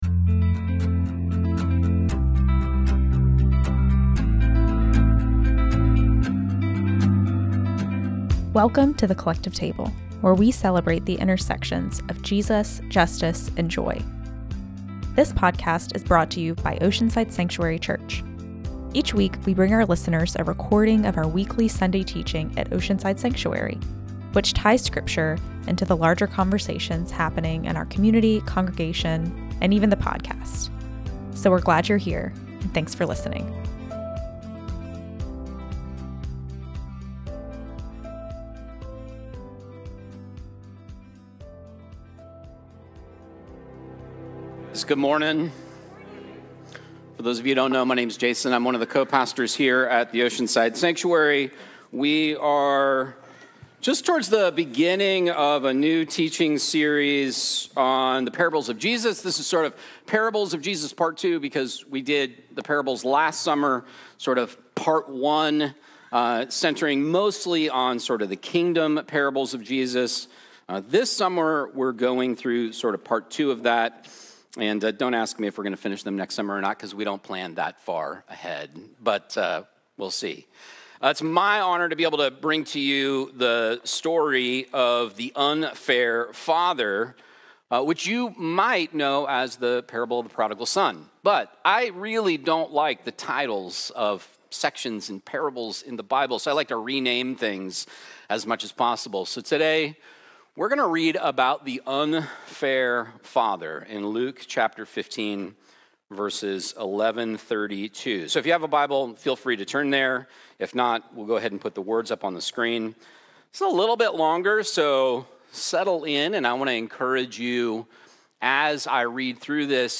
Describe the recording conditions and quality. Each week, we bring our listeners a recording of our weekly Sunday teaching at Oceanside Sanctuary, which ties scripture into the larger conversations happening in our community, congregation and podcast.